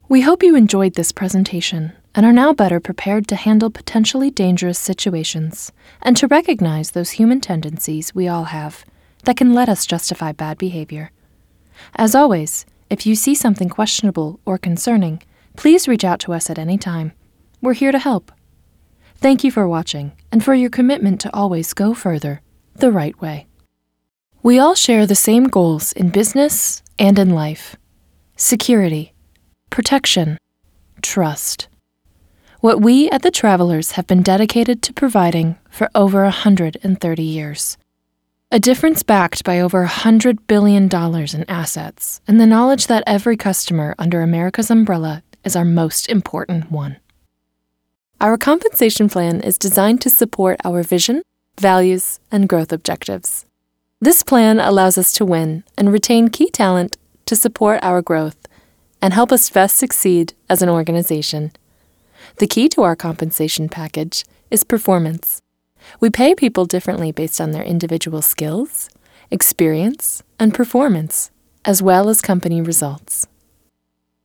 English (North American)
Voice Age
Young Adult